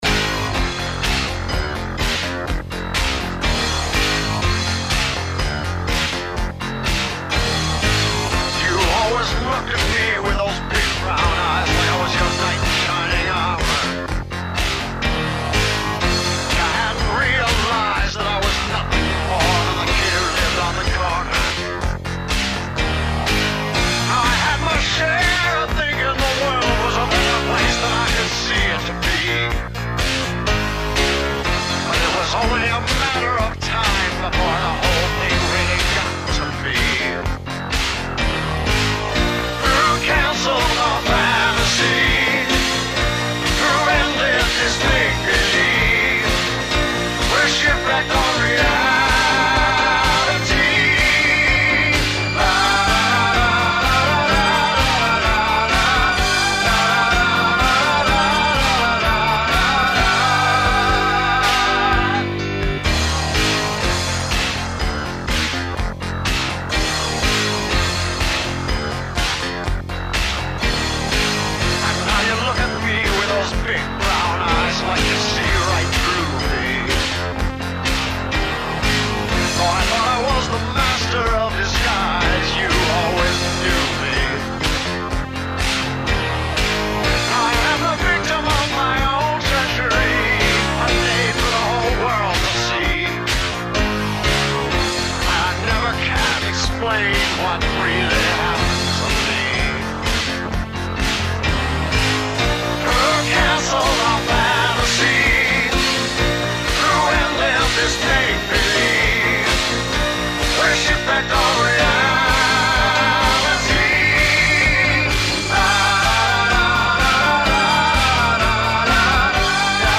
The snare drum gives the 80's timeframe away (ala Betty Davis Eyes).  For all my former music students, this song contains a tiers (tierce) de picardie.